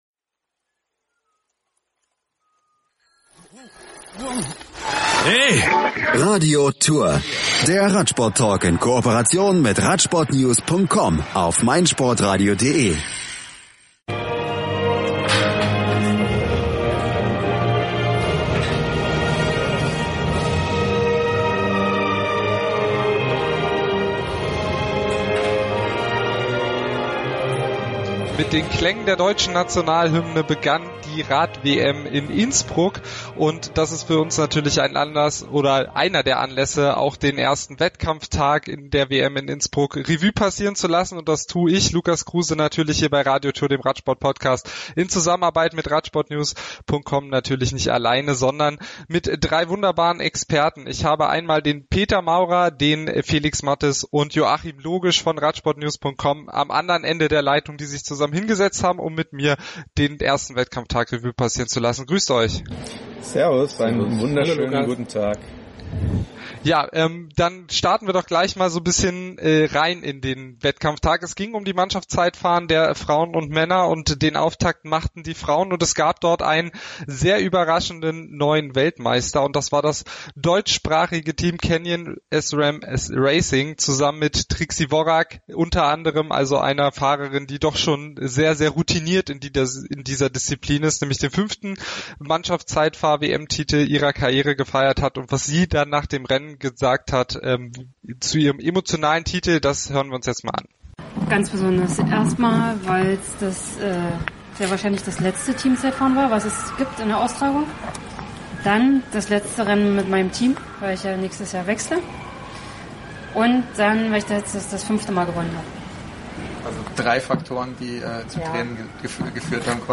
Radio Tour Podcast